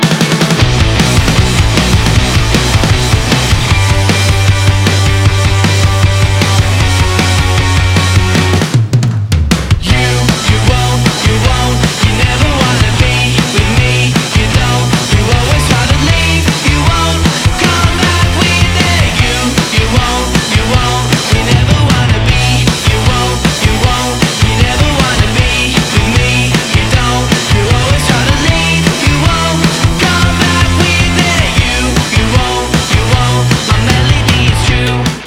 • Качество: 192, Stereo
Веселый сингл от новой рок-группы